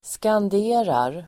Uttal: [skand'e:rar]